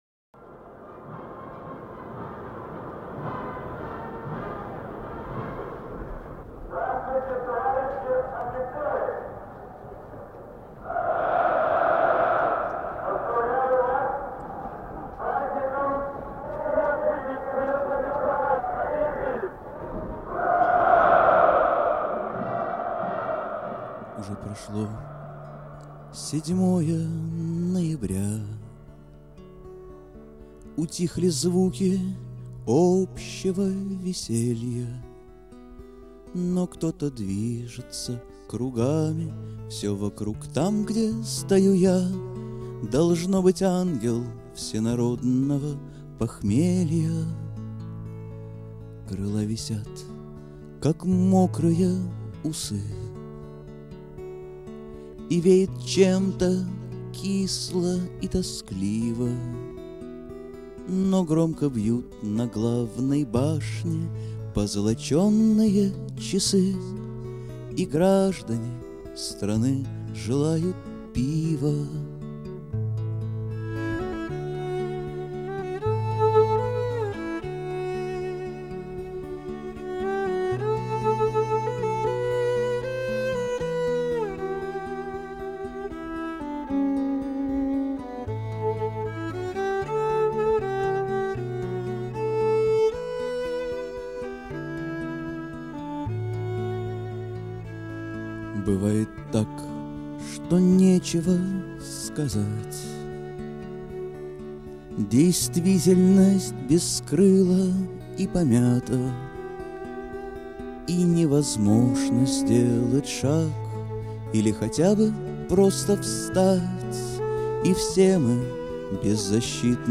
Genere: Rock